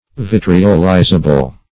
Search Result for " vitriolizable" : The Collaborative International Dictionary of English v.0.48: Vitriolizable \Vit"ri*ol*i`za*ble\, a. Capable of being converted into a vitriol.
vitriolizable.mp3